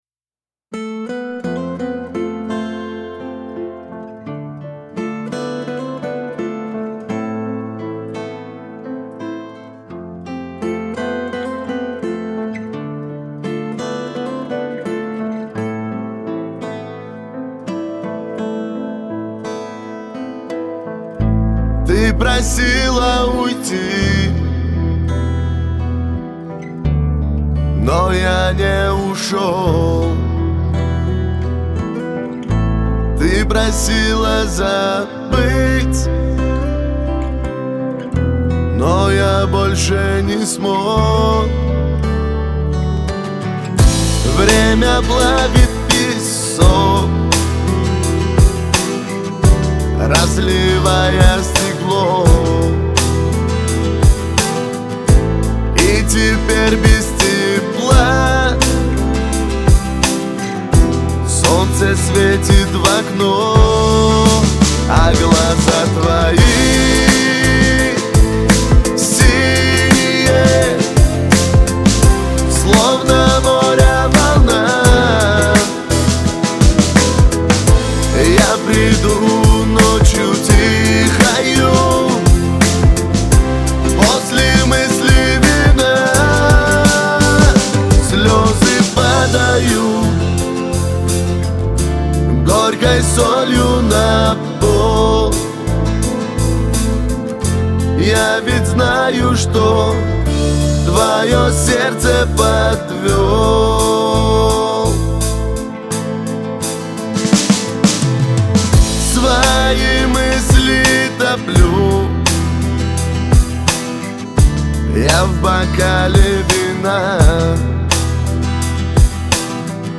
• Жанр: Русские песни